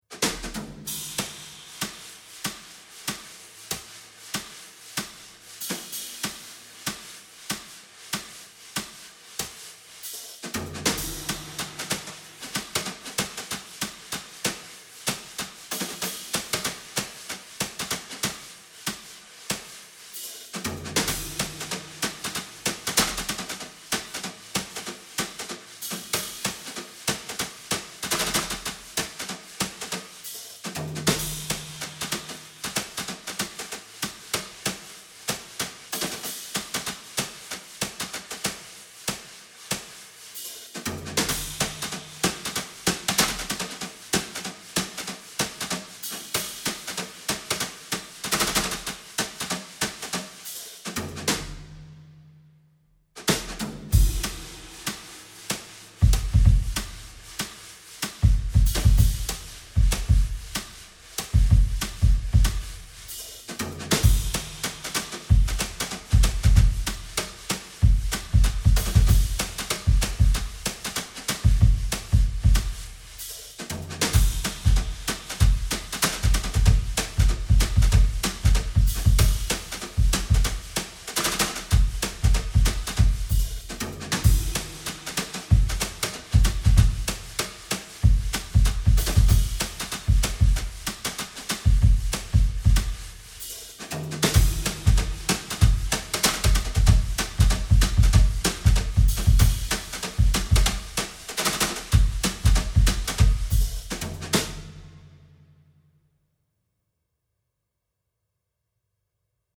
drums only